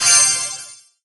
emit_gems_01.ogg